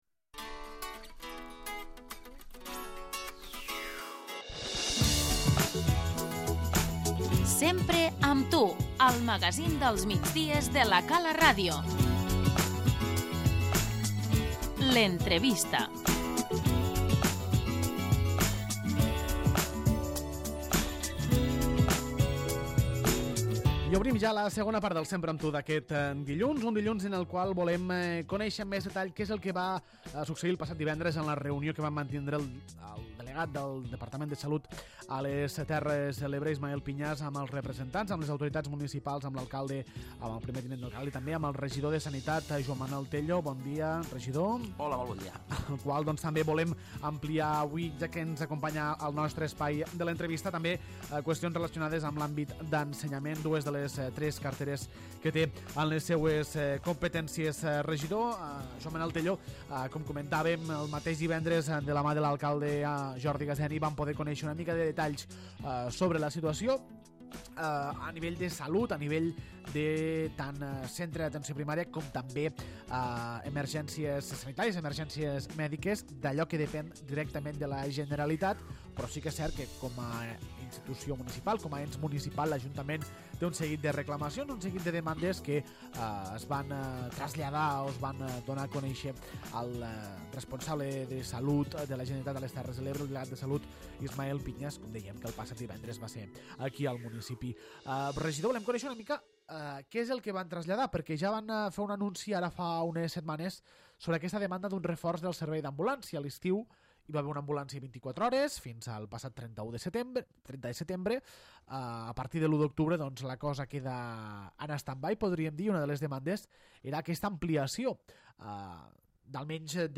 L'entrevista - Joan Manel Tello, regidor de Sanitat i Governació